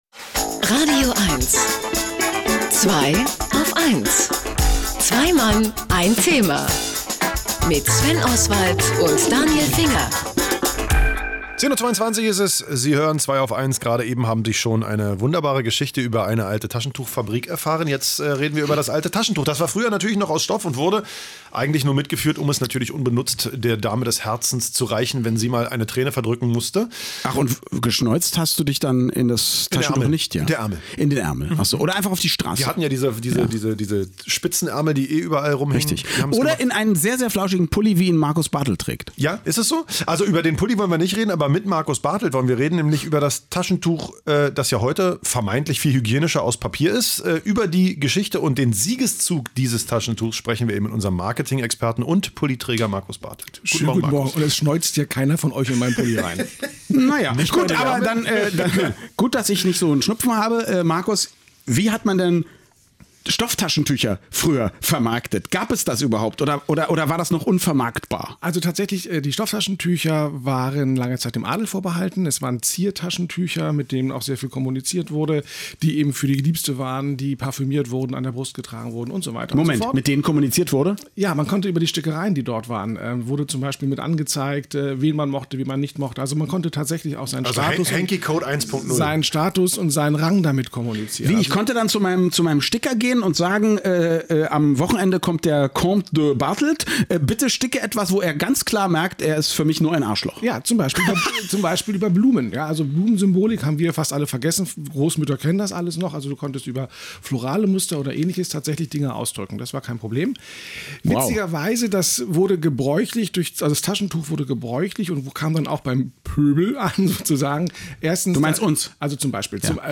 Im Zuge dieser vergrippten Zeiten ward ich just zu diesem Thema bei radioeins ins Zweiaufeins-Studio geladen: